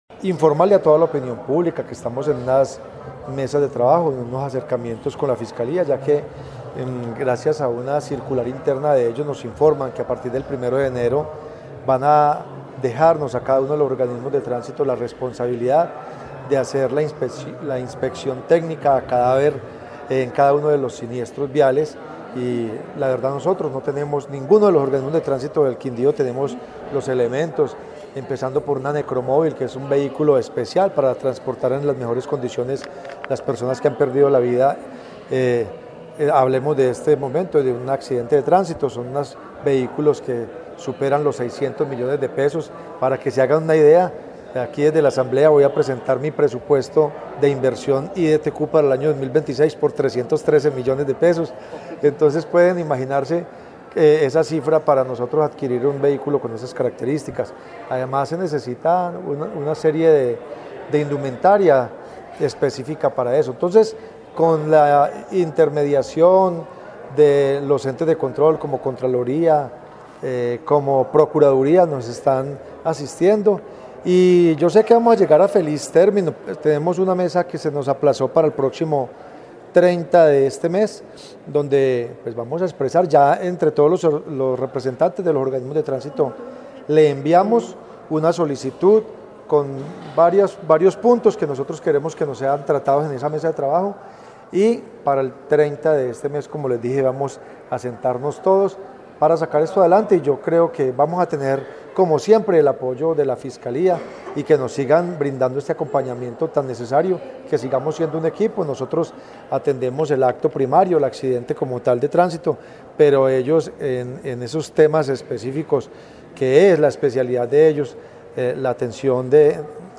Director del IDTQ Quindío